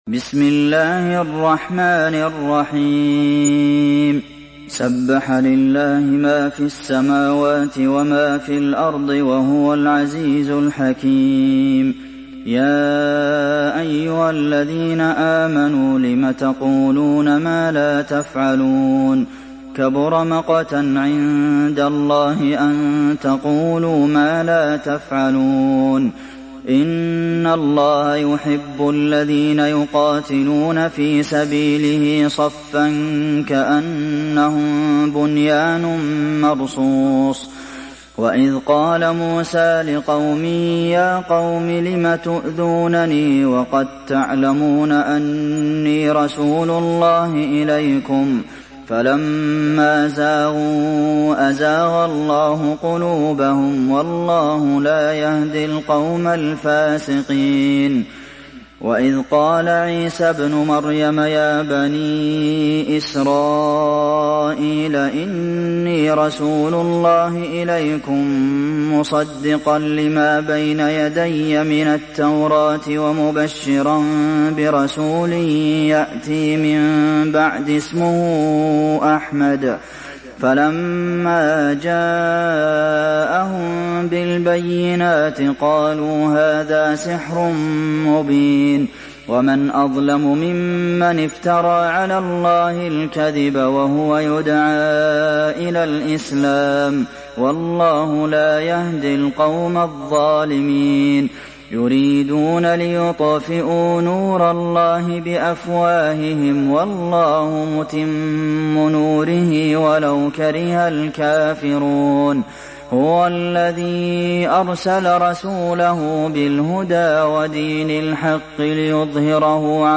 Surah As Saf mp3 Download Abdulmohsen Al Qasim (Riwayat Hafs)